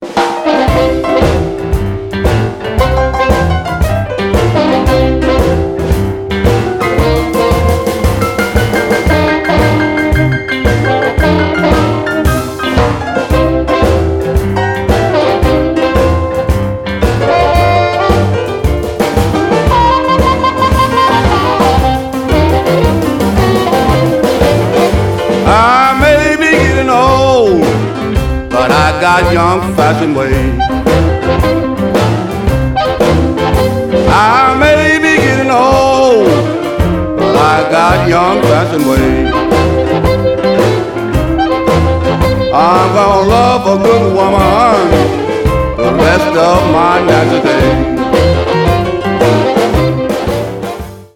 traditional blues